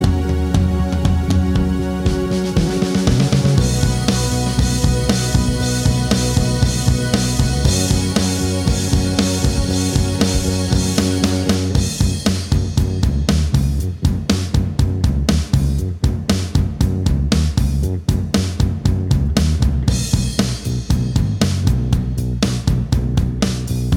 No Guitars Pop (2010s) 5:01 Buy £1.50